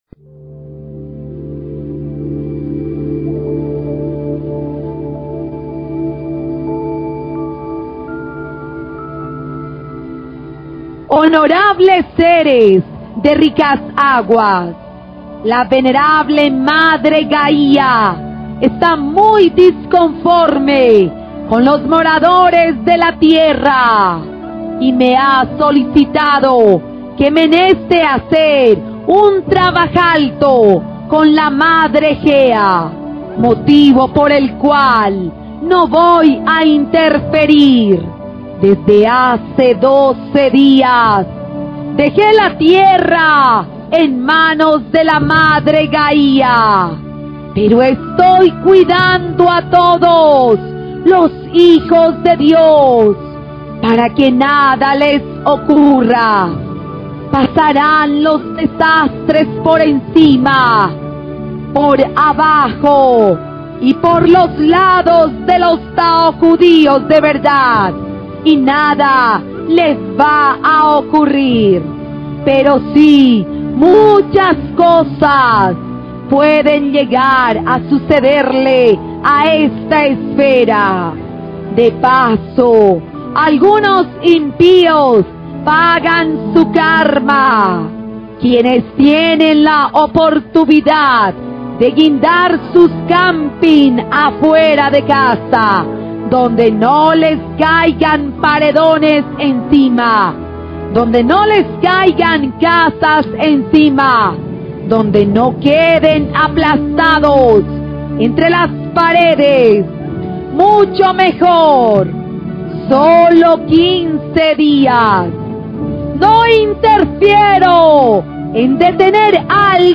Konferensia